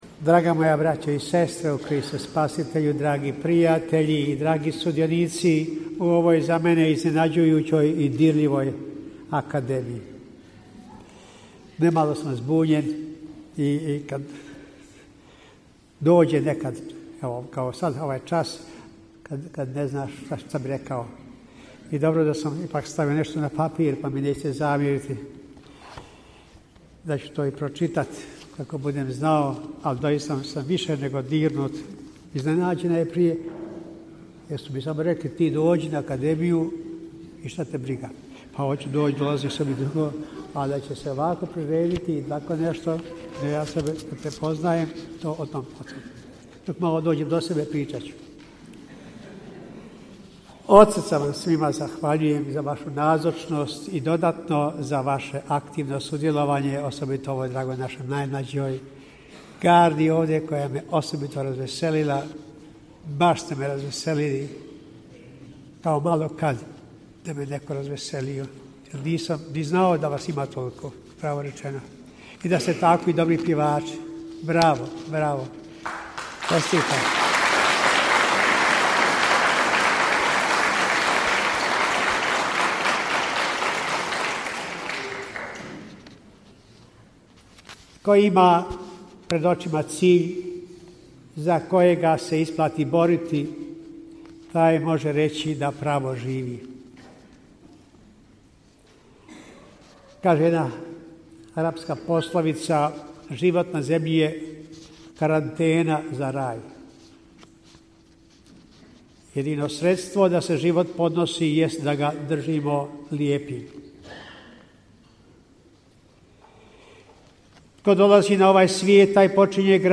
Audio: Zahvalna riječ mons. Franje Komarice na kraju svečane akademije u banjolučkoj katedrali
U večernjim satima, 1. ožujka 2024., u katedrali sv. Bonaventure u Banjoj Luci nakon zahvalnog Euharistijskog slavlja upriličena je svečana akademija u čast dosadašnjeg biskupa banjolučkog mons. Franje Komarice. Na kraju akademije mons. Komarica uputio je zahvalnu riječ koju prenosimo u cijelosti: